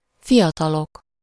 Ääntäminen
France: IPA: [ʒœ.nɛs]